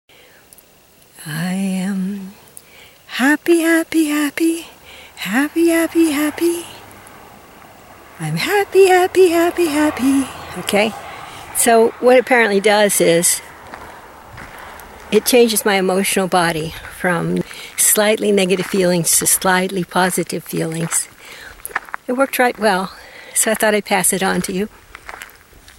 Then I thought I would devise a very simple song.